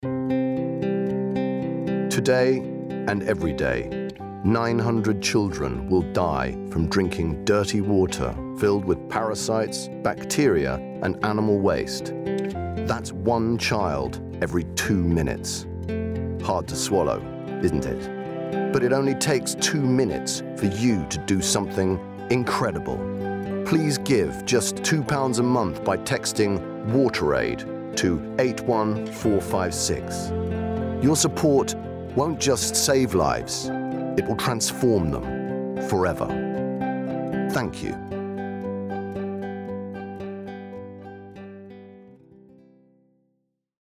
Murray has a deep, expressive and brilliantly theatrical tone to his voice.
• Male
Murray Lachlan Young – Water Aid. Compassionate and compelling